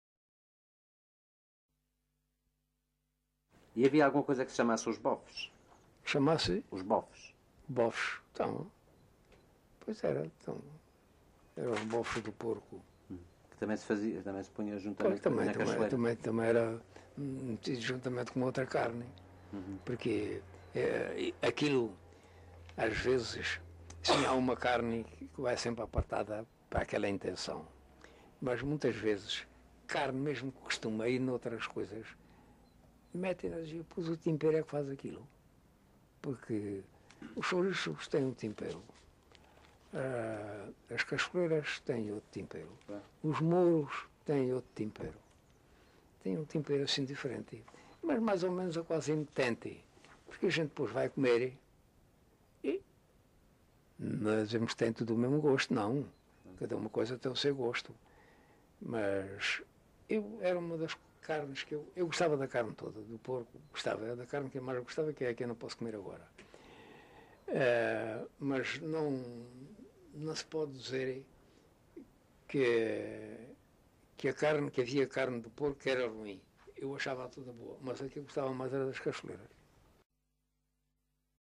LocalidadeCabeço de Vide (Fronteira, Portalegre)